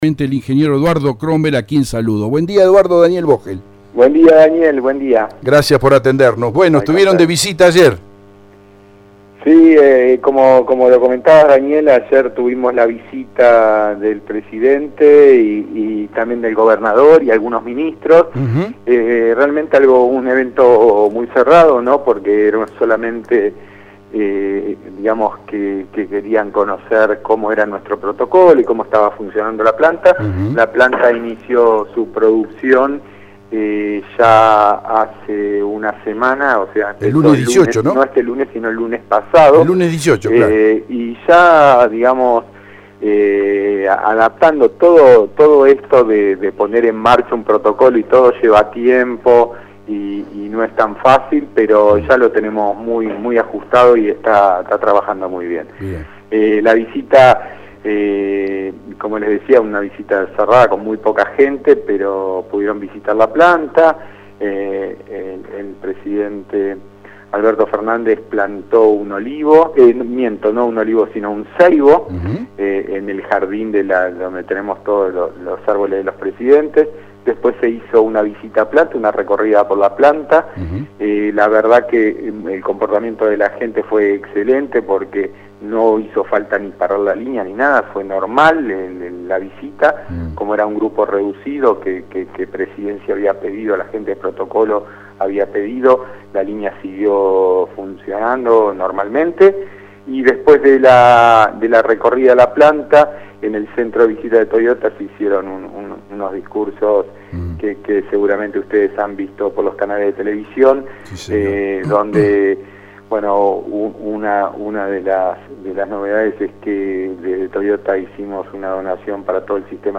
ENTREVISTA DE LA MAÑANA DE HOY